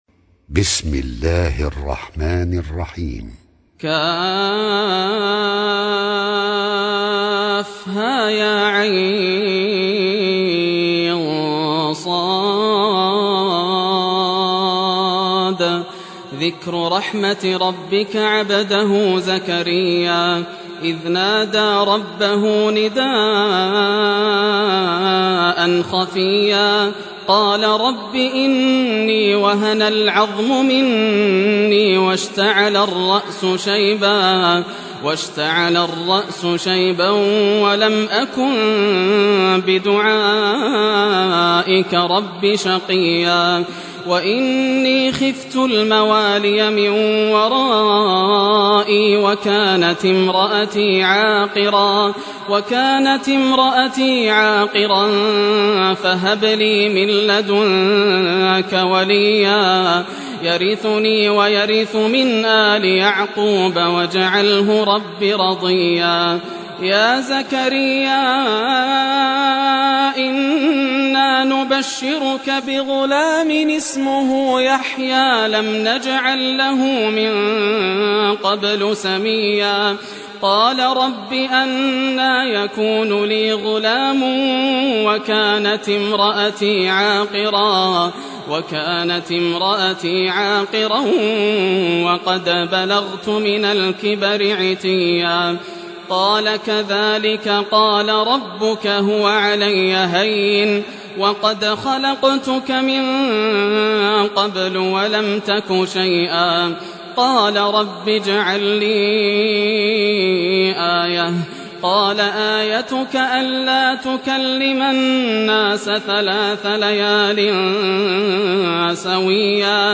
Surah Maryam Recitation by Sheikh Yasser Dosari
Surah Maryam, listen or play online mp3 tilawat / recitation in Arabic in the beautiful voice of Sheikh Yasser al Dosari.